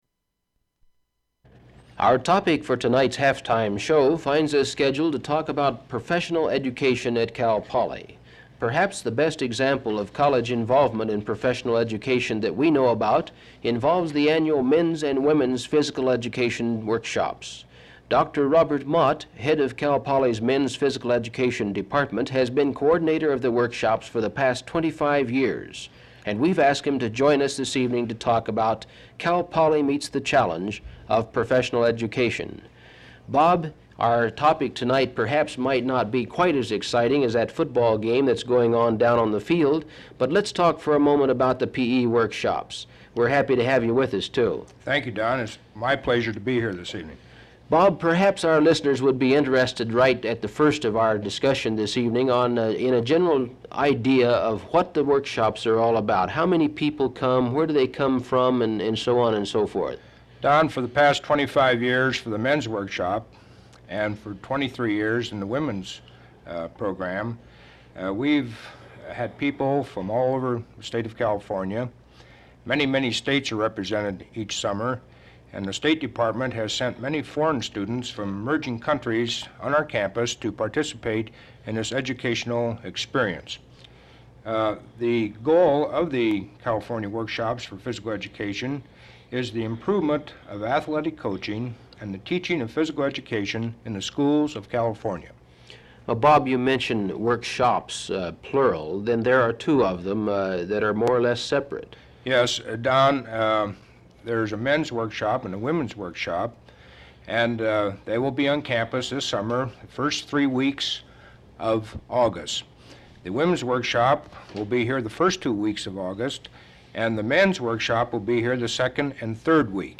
• Open reel audiotape